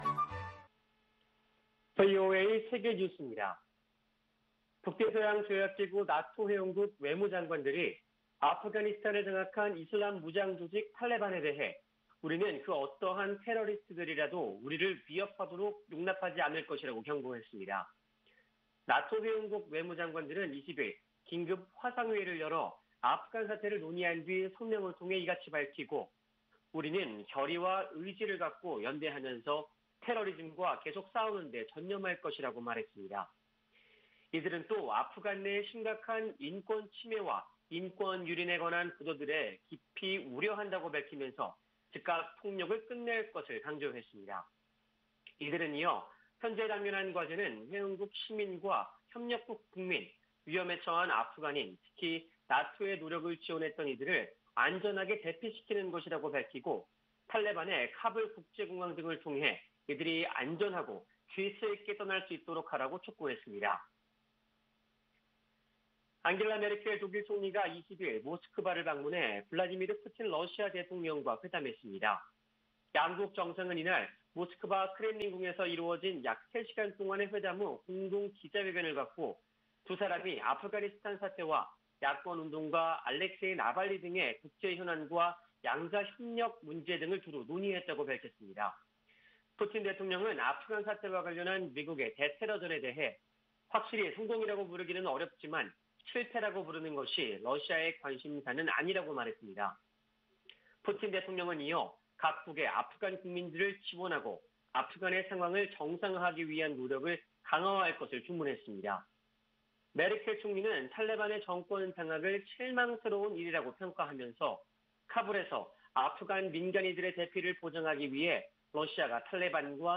VOA 한국어 아침 뉴스 프로그램 '워싱턴 뉴스 광장' 2021년 8월 21일 방송입니다. 조 바이든 미국 대통령은 한국 등 동맹국과 아프가니스탄의 상황이 근본적으로 다르다고 말했습니다. 북한 남포 항에서 최근 하얀 포대를 실은 대형 선박들이 잇따라 포착되고 있습니다. 북한 정권이 최근 ‘부정부패 척결’을 강조하며 ‘돈주’라 불리는 신흥 자본가들을 압박하고 있다는 분석이 나왔습니다.